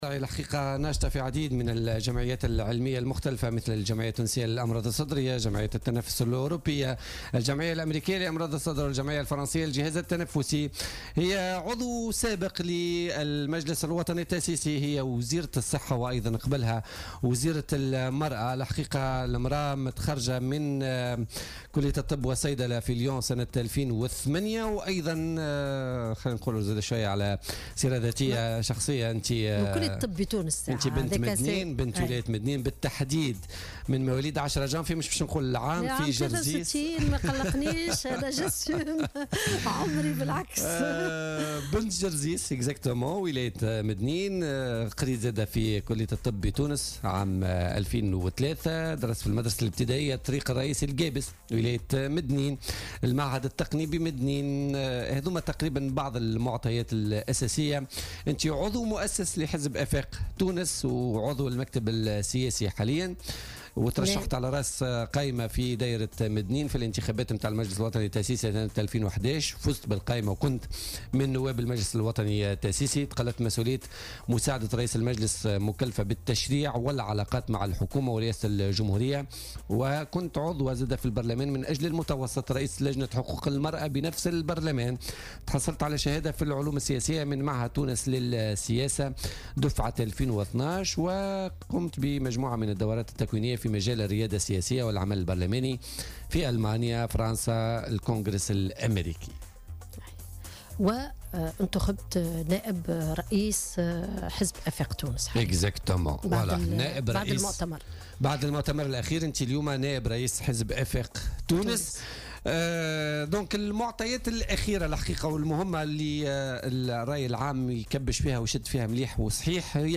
قالت سميرة مرعي وزيرة الصحة السابقة ضيفة بولتيكا اليوم الثلاثاء 19 سبتمبر 2017 إنها عملت داخل وزارة الصحة في العمق لإصلاح مشاكل المنظومة الصحية الكبرى .